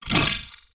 ghost_armor_attack9.wav